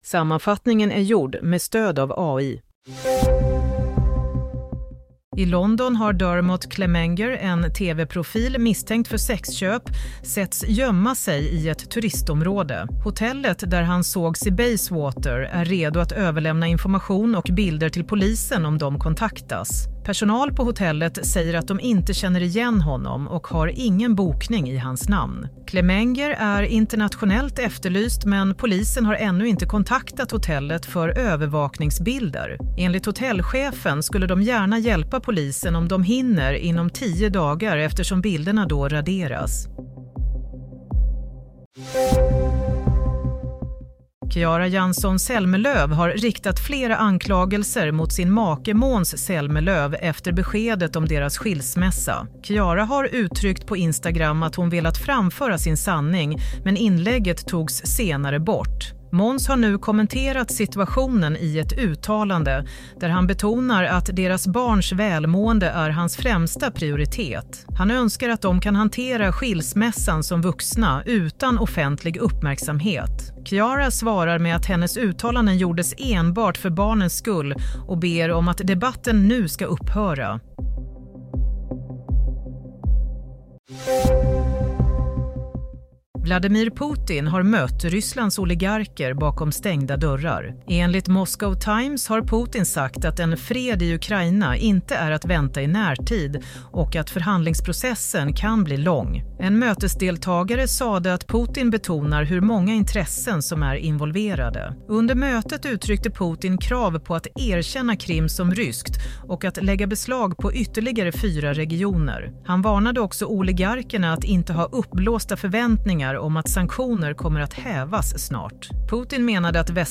Nyhetssammanfattning - 20 mars 22.00